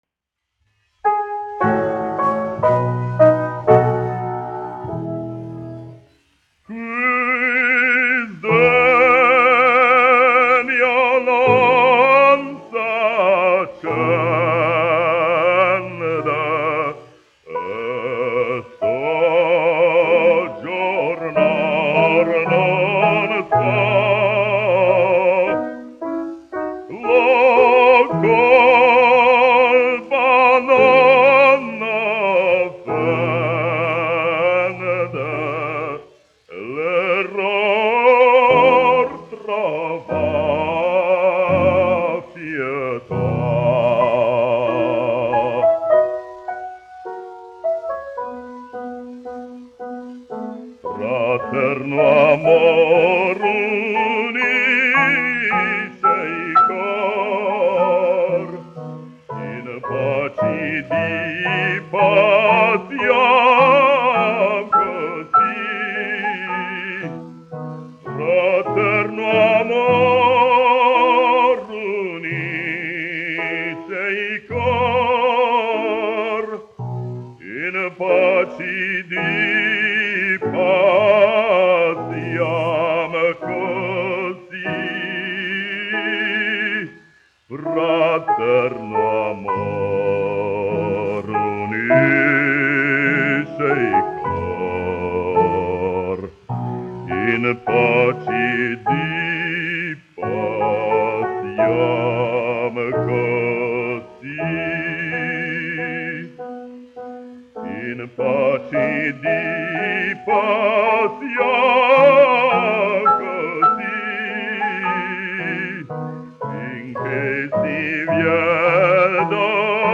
1 skpl. : analogs, 78 apgr/min, mono ; 25 cm
Operas--Fragmenti, aranžēti
Latvijas vēsturiskie šellaka skaņuplašu ieraksti (Kolekcija)